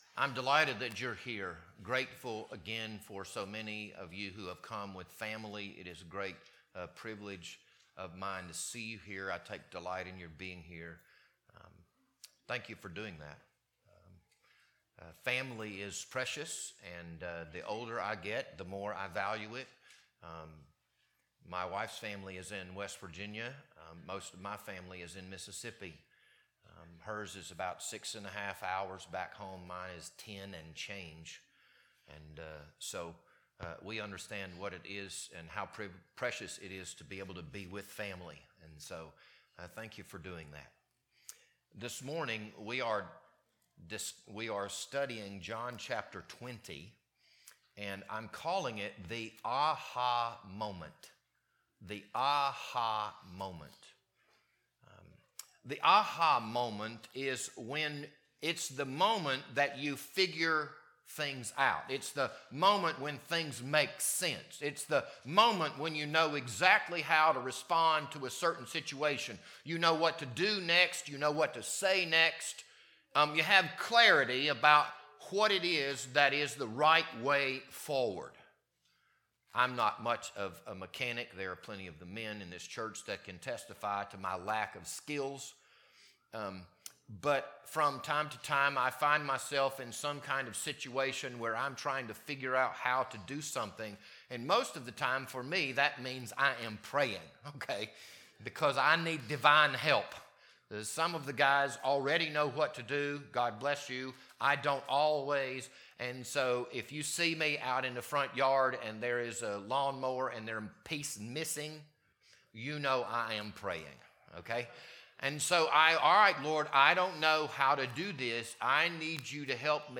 This Sunday morning sermon was recorded on April 5th, 2026.